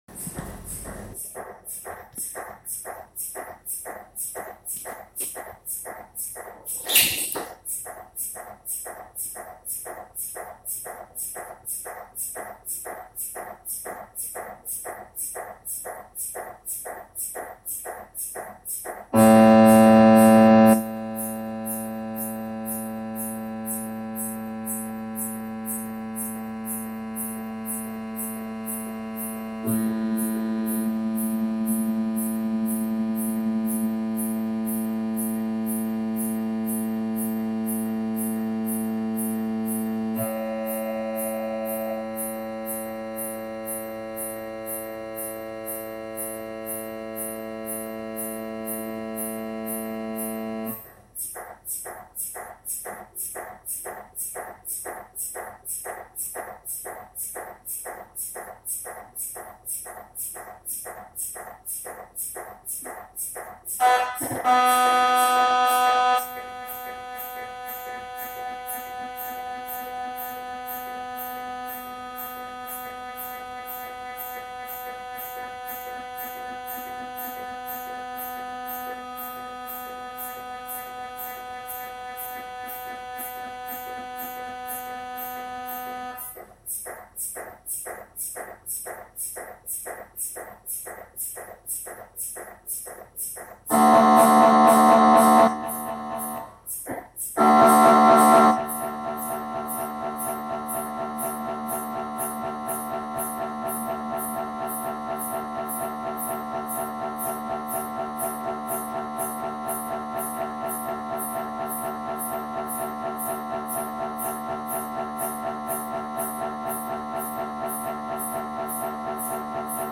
Шум при МРТ
Громкие ритмичные постукивания, гудение и вибрация, которые слышит пациент, — это «голос» работы мощных градиентных катушек.
Звук может меняться в течение исследования: от монотонного гула до прерывистого стука, в зависимости от того, какая область тела обследуется и какая программа сканирования активна.
А так звучит МРТ во время проведения исследования. В зависимости от выбранной программы и зоны сканирования меняется ритм, частота и громкость — своеобразная «техника-мелодия» томографа.
zvuki-rab-mrt-1-1.mp3